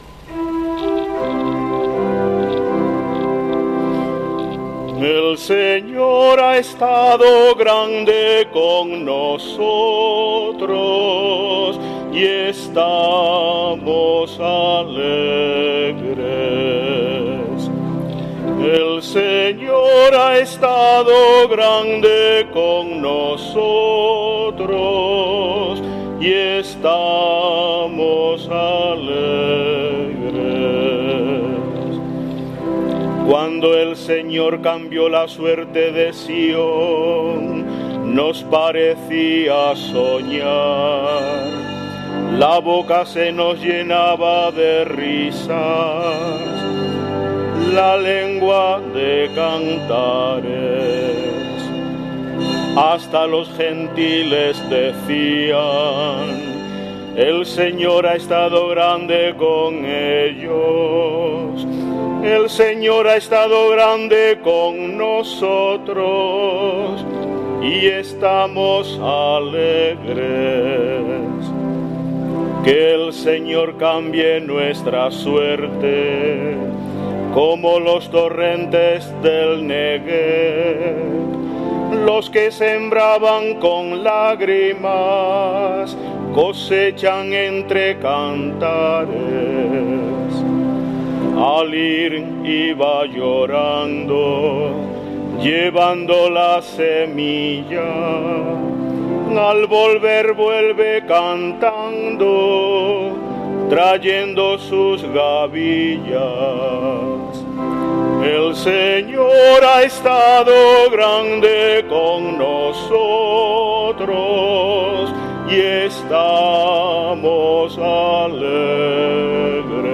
Salmo Responsorial 125/ 1-6